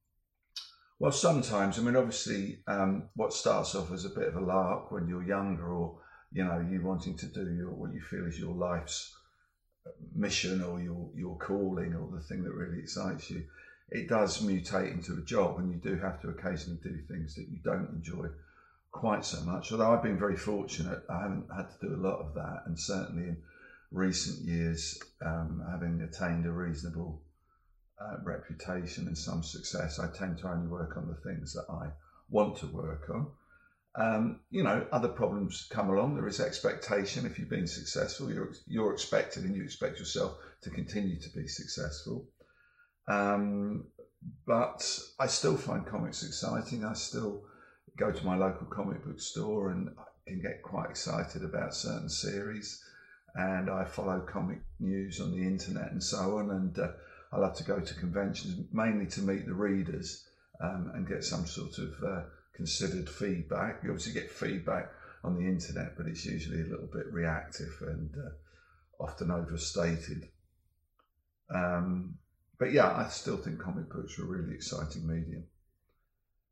Dave Gibbons interview: Are you still motivated?